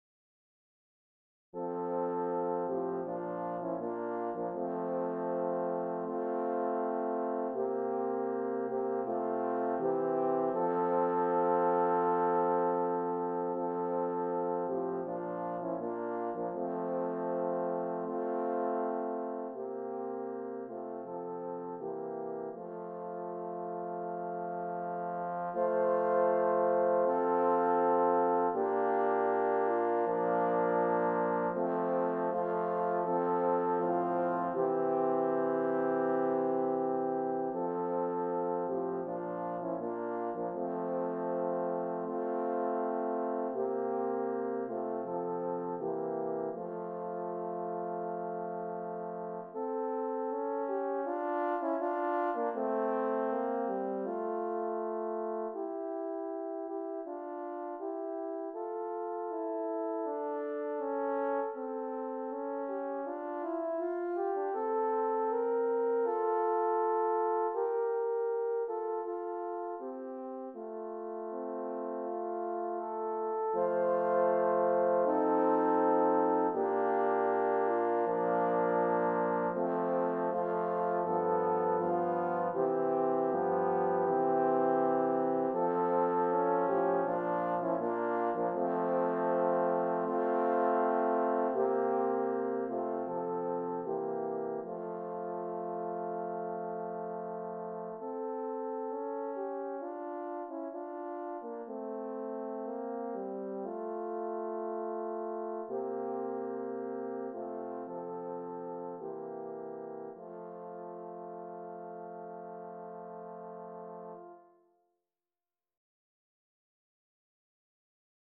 Here is the old public domain hymn named PASS ME NOT by Doane that I have arranged for horn quartet.